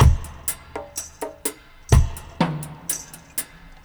62-FX+PERC4.wav